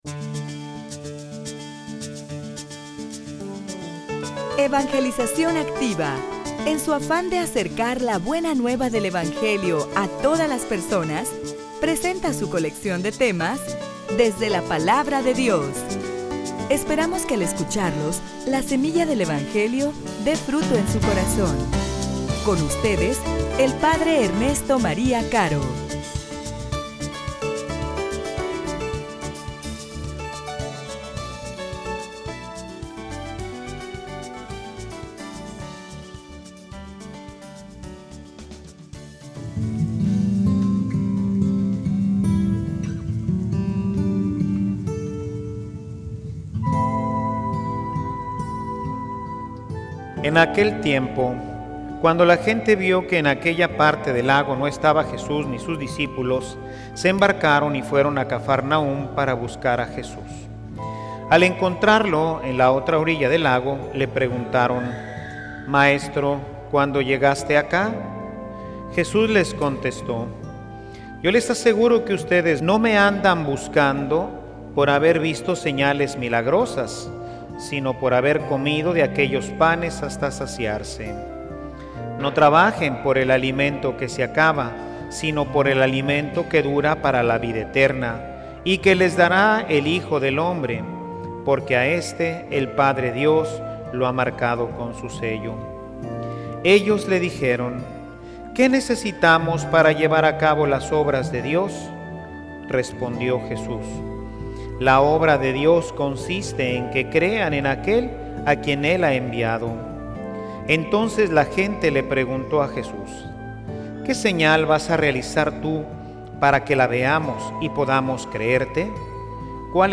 homilia_El_Pan_que_si_sacia.mp3